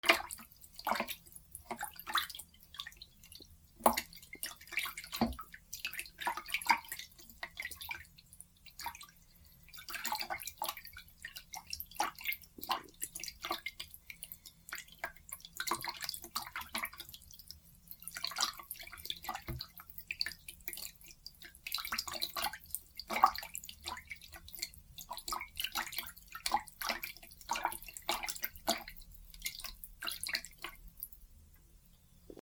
水音 洗面器で水をぱちゃぱちゃ
『パチャパチャ』